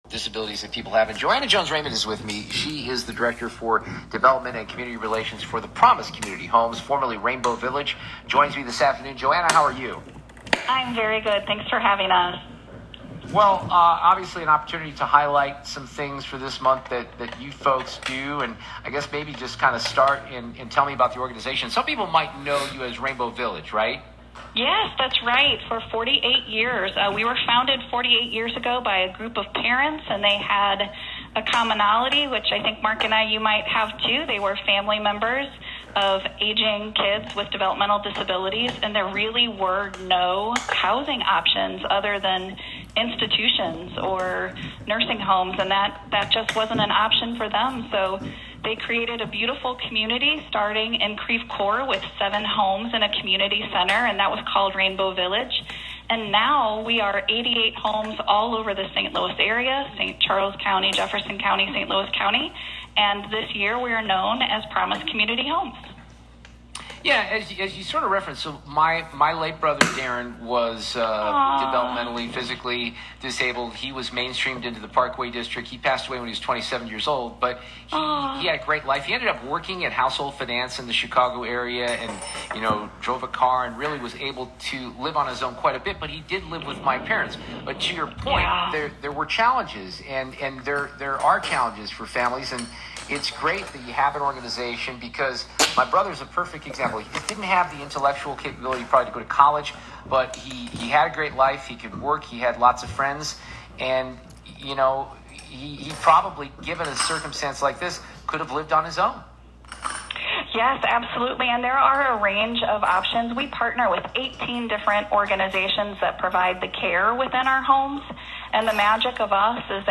KMOX Interview